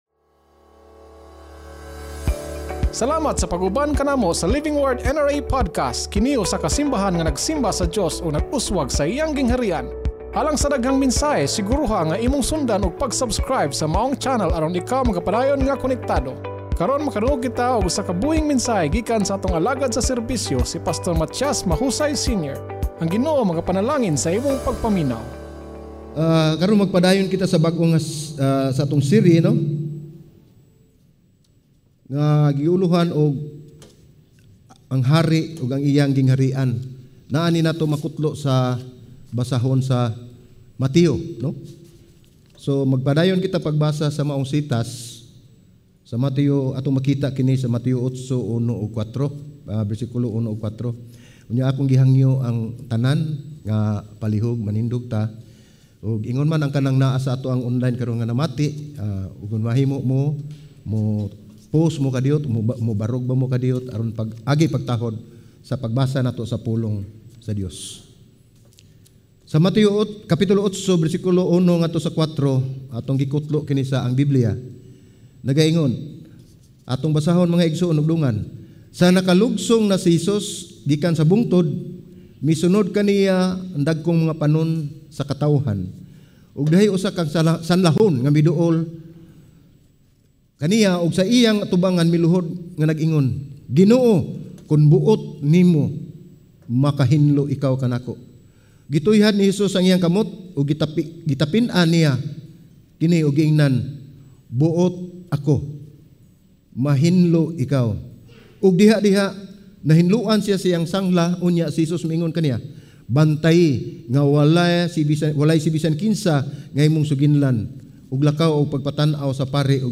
Salvation ANG HARI UG ANG IYANG GINGHARI-AN Watch Listen Read Save Andam ba ikaw nga mohimo usab niini ngadto sa uban? Sermon